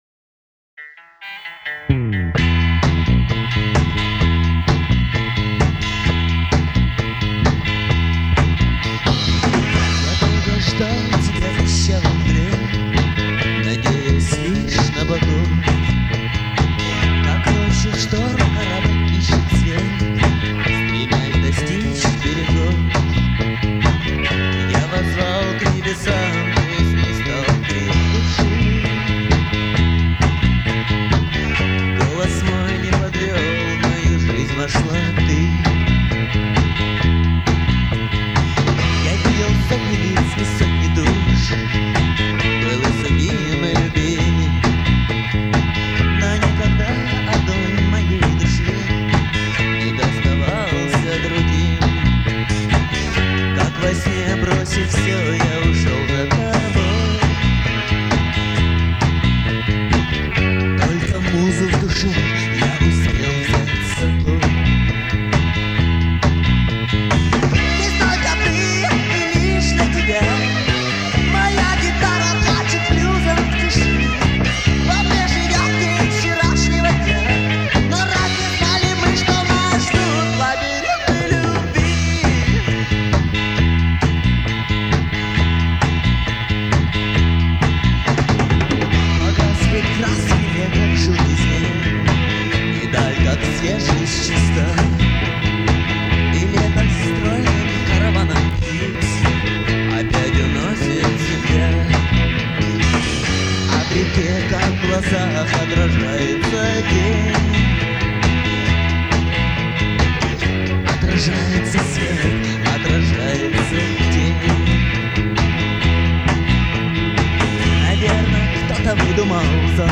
Альбом записан летом 1991 года в Хабаровском Горводоканале
клавишные
барабаны
гитара, вокал
губная гармошка, тамбурин